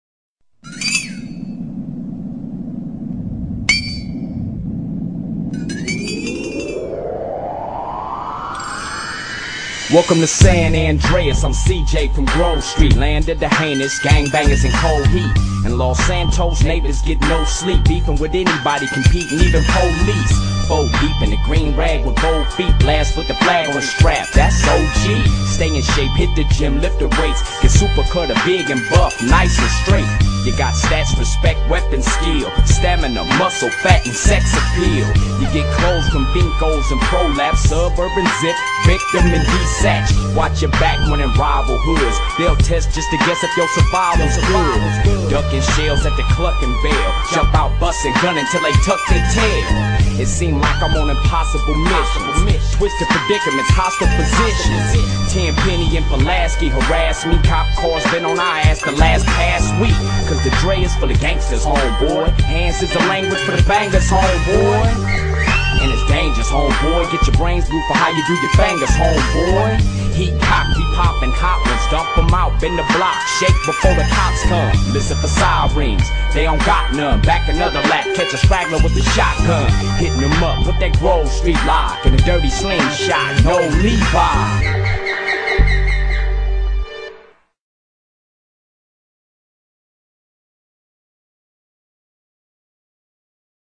0 GTA San Andreas CJ rap.mp3